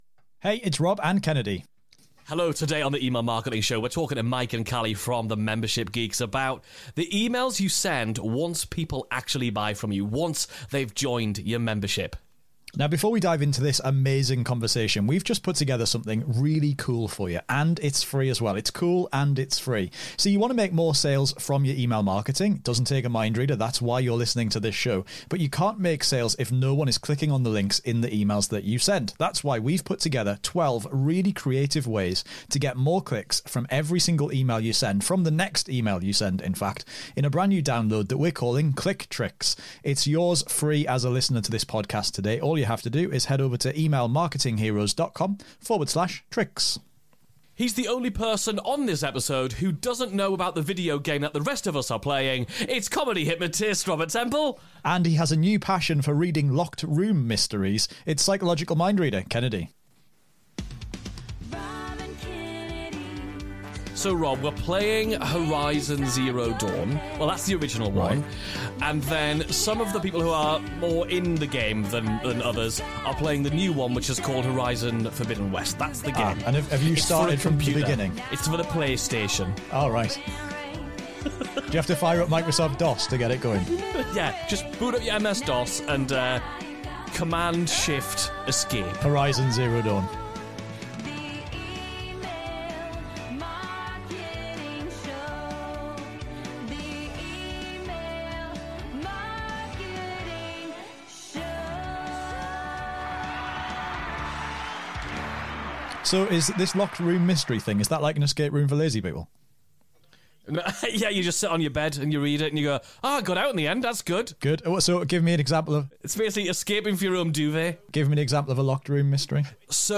This conversation is all about the importance of communicating with the people who are already inside your membership.